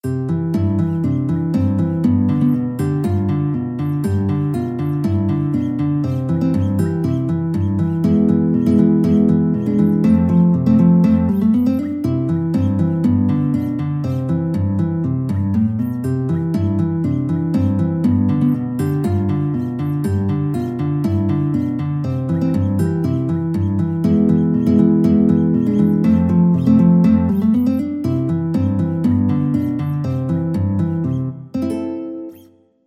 4/4 (View more 4/4 Music)
Guitar  (View more Easy Guitar Music)
Traditional (View more Traditional Guitar Music)